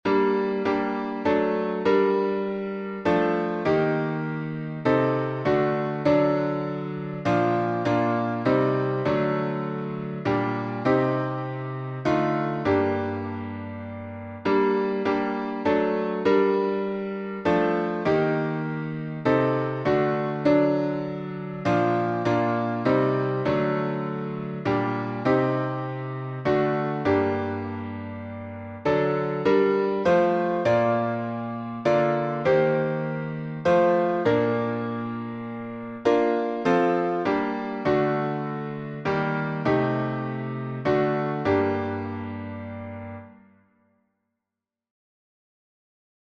Friedrich Layriz, 1849 Key signature: B flat major (2 flats) Time signature: 3/4 Meter: 9.8.9.8.8.8. Public Domain 1.